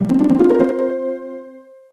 This file is an audio rip from a(n) SNES game.